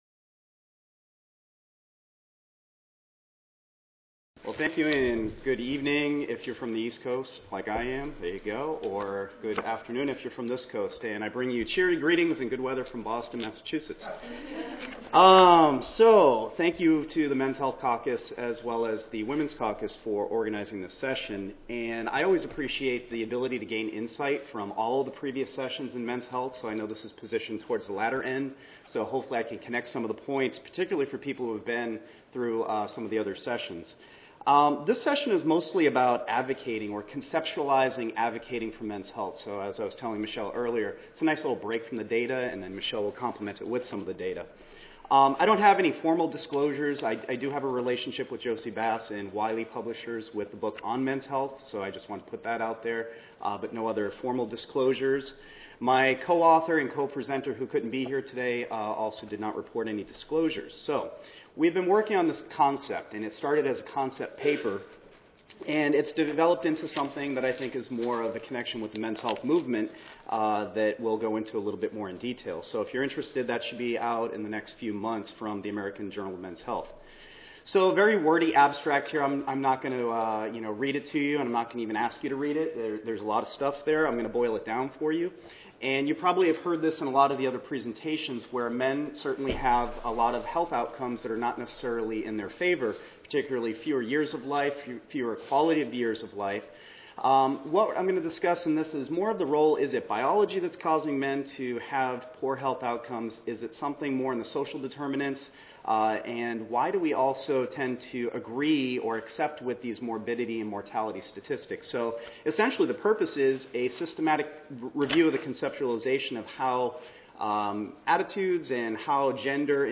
This is a panel discussion jointly sponsored by the APHA women�s and men�s health caucuses to advance the dialog and understanding regarding gender health issues and needs. This program will be a four-part moderated dialog between women and men health experts regarding health and wellness issues that impact both genders across the lifespan.